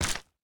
resin_break3.ogg